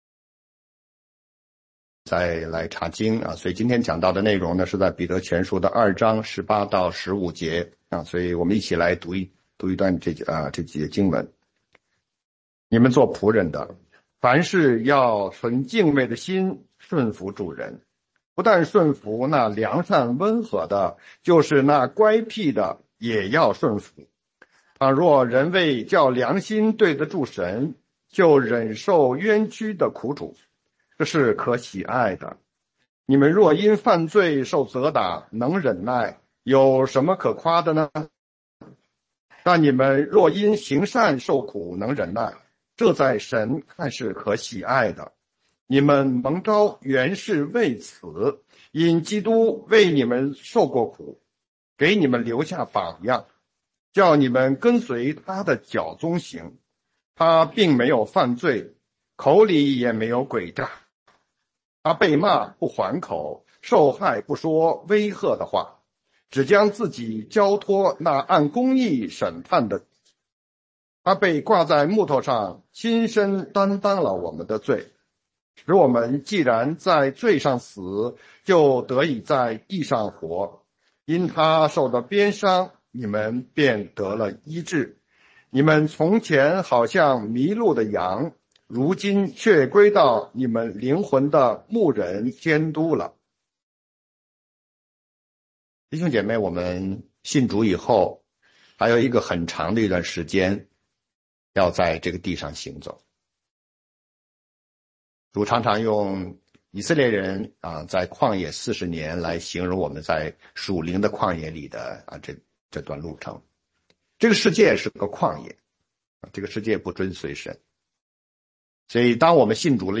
全中文讲道与查经